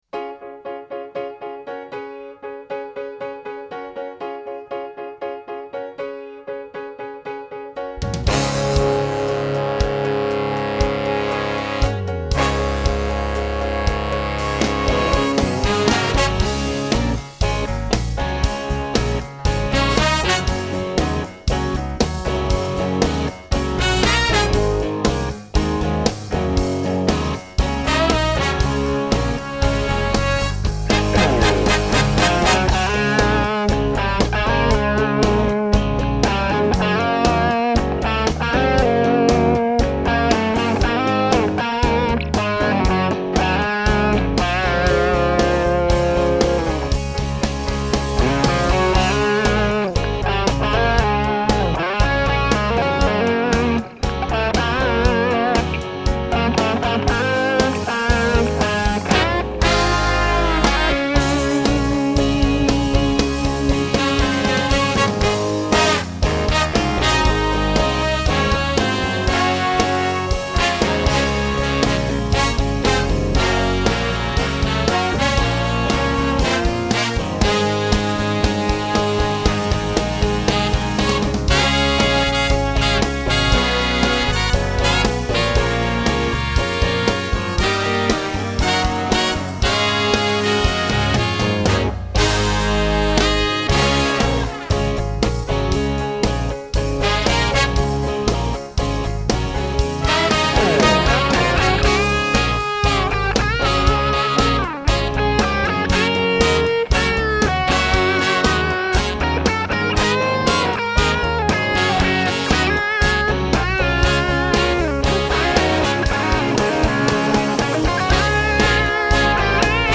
For now, here are some rough mix samples.